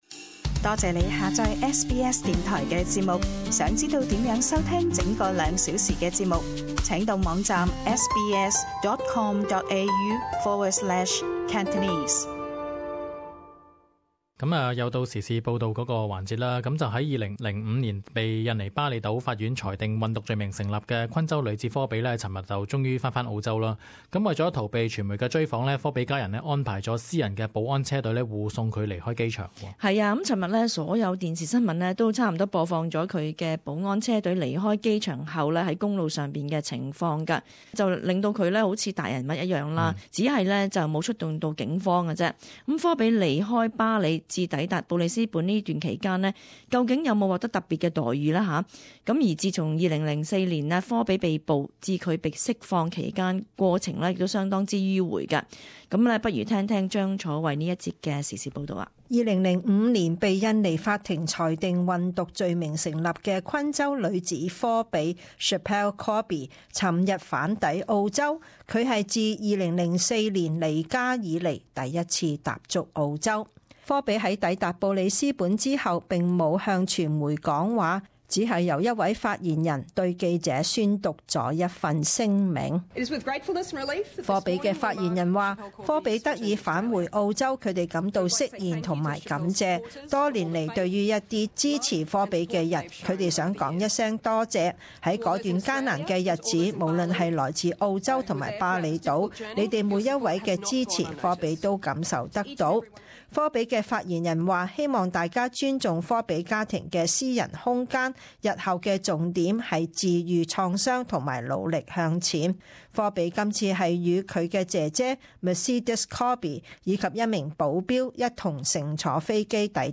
【時事報導】傳媒鋪天蓋地報導科比回澳